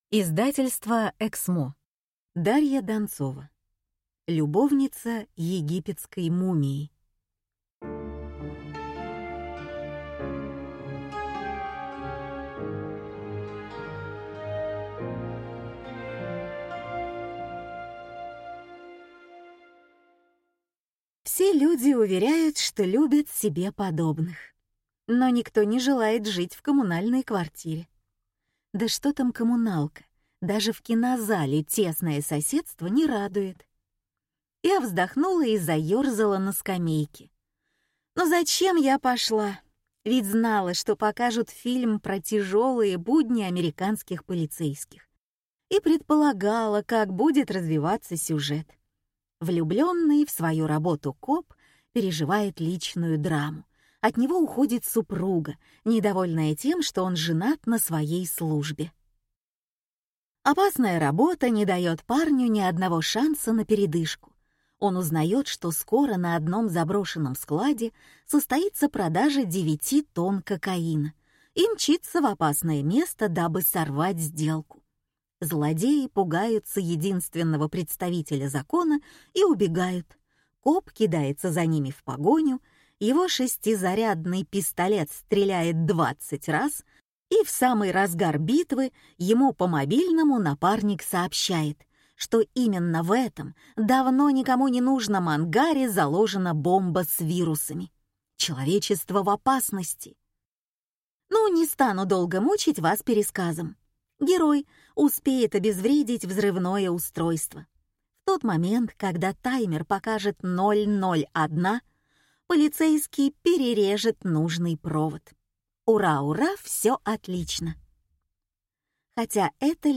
Аудиокнига Любовница египетской мумии | Библиотека аудиокниг